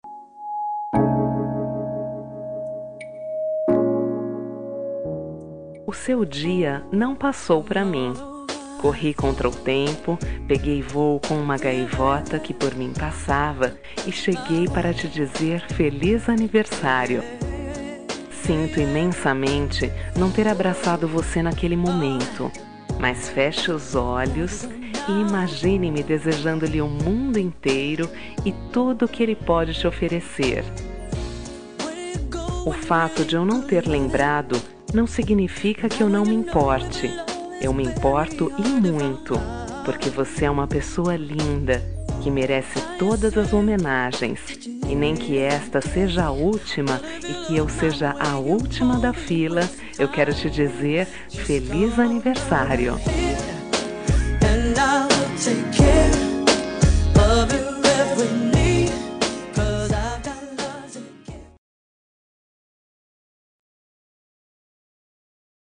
Aniversário Atrasado – Voz Feminina – Cód: 2487